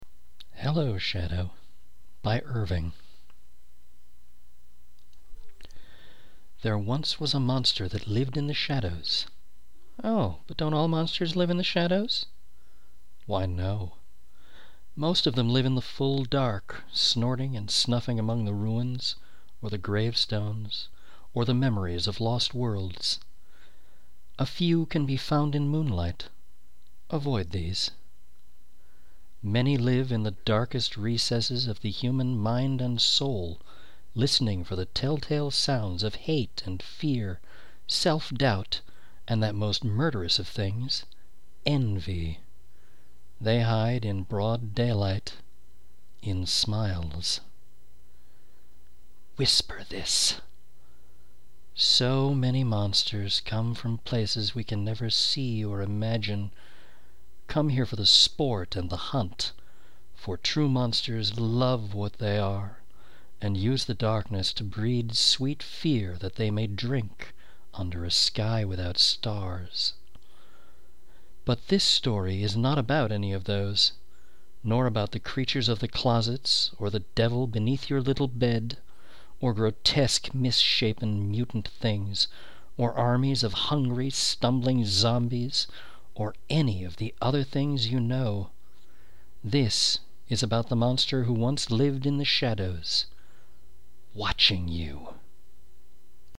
2008 Halloween Poetry Reading